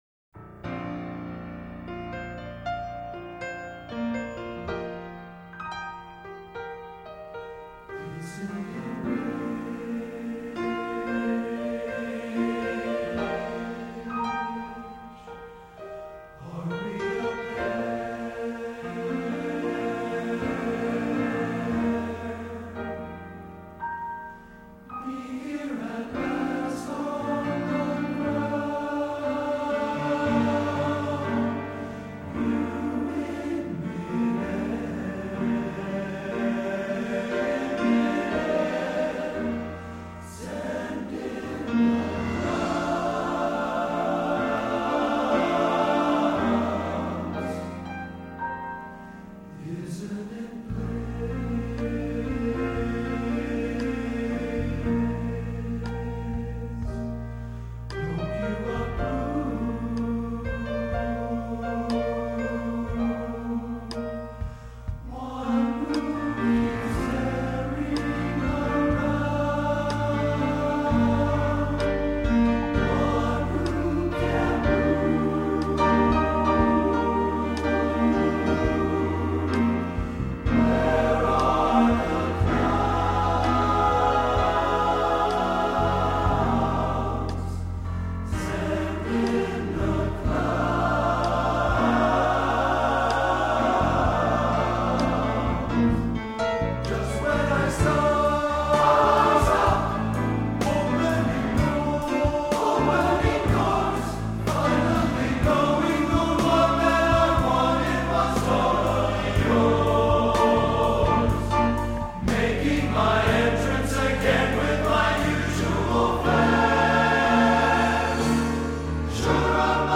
Genre: Broadway | Type: